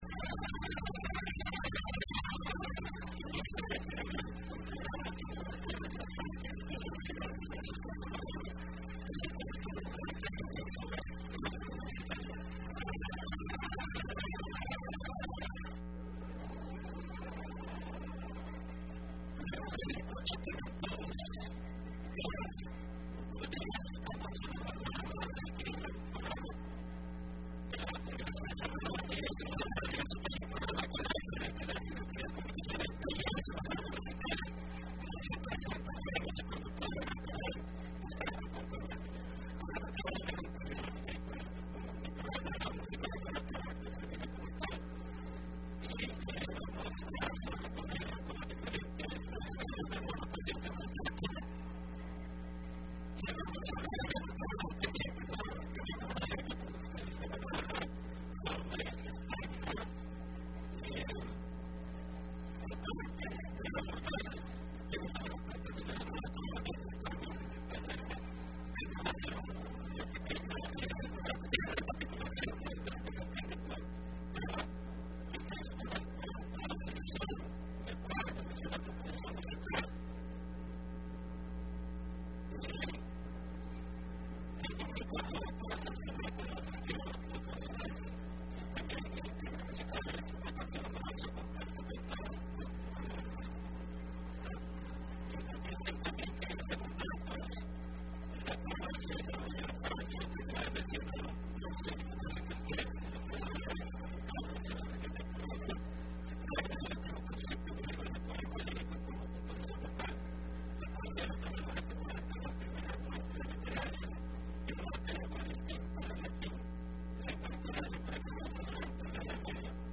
Entrevista programa Aequilibrium (14 abril 2015): Música de la nueva trova como expresión artística cultural , segunda parte y final